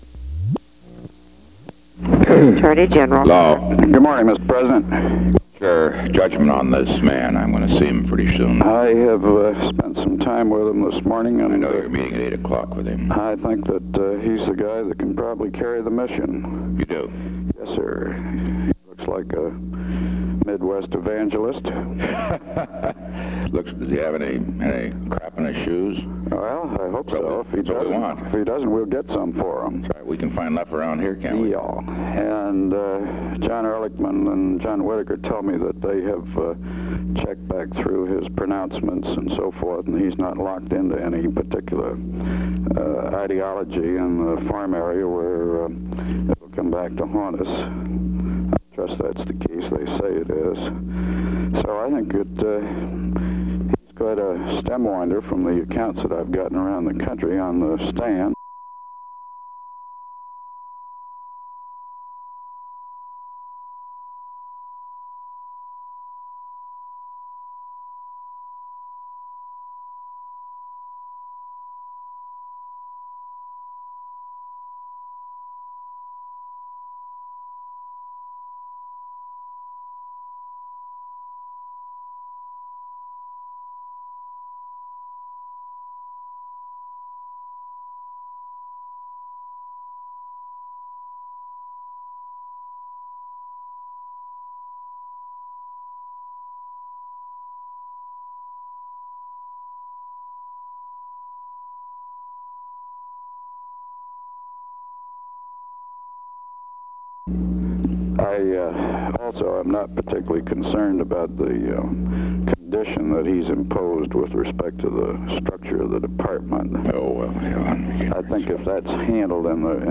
Conversation No. 14-34
Location: White House Telephone
The President talked with the White House operator.
The President talked with Mitchell.